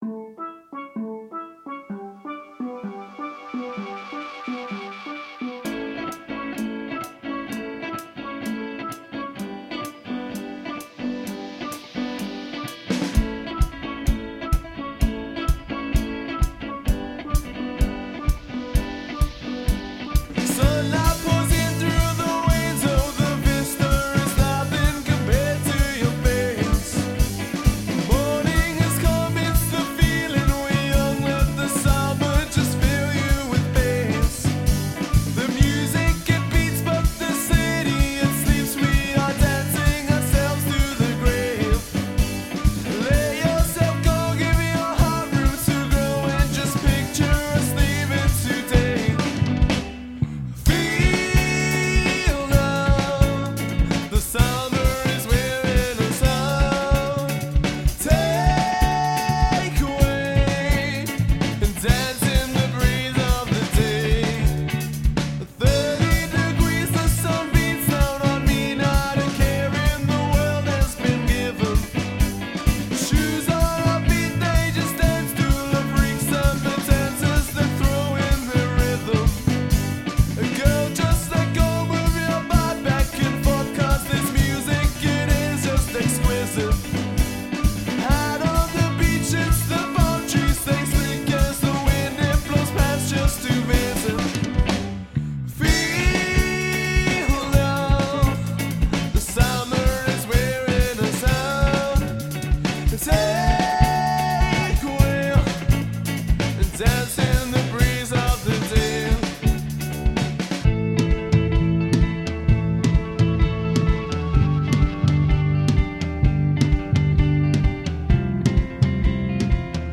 Indie-funk group
percussion